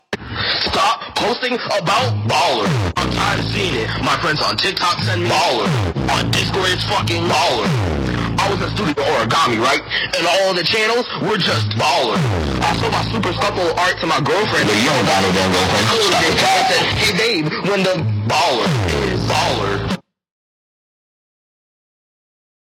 stop-posting-about-baller-made-with-Voicemod-technology.mp3